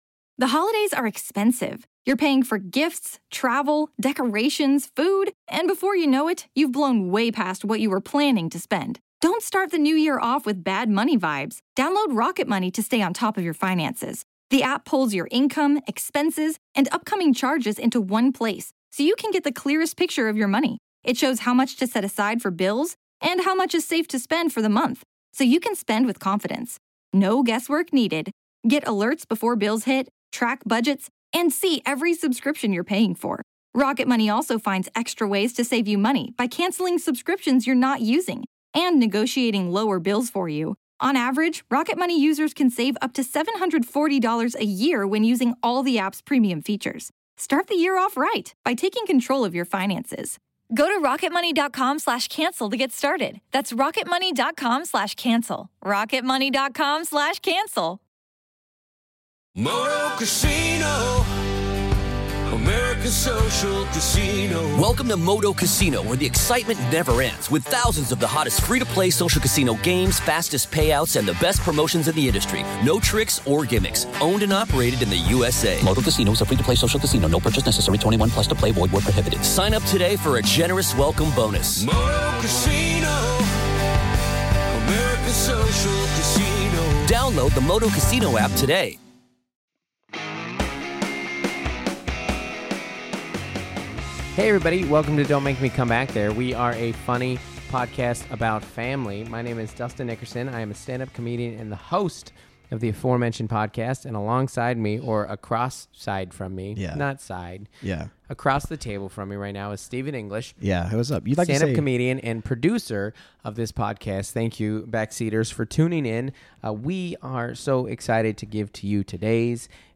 A Conversation